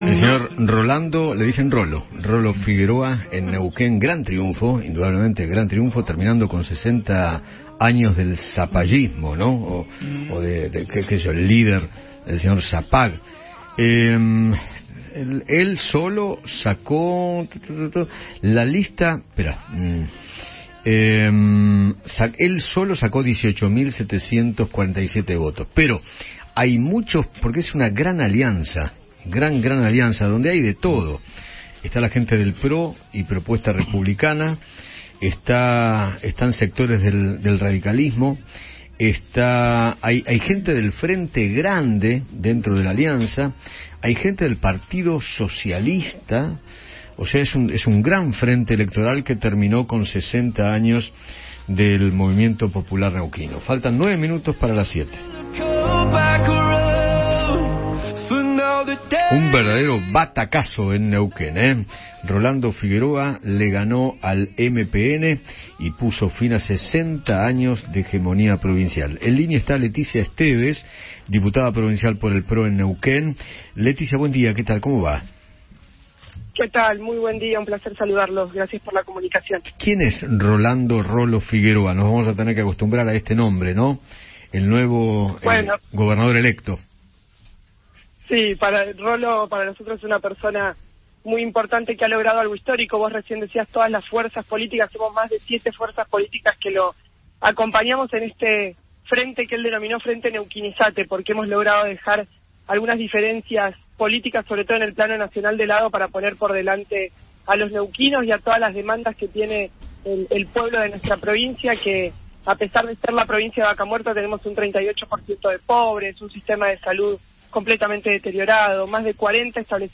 Leticia Estévez, diputada provincial por el PRO en Neuquén, conversó con Eduardo Feinmann sobre el triunfo de Rolando Figueroa en la gobernación de su provincia.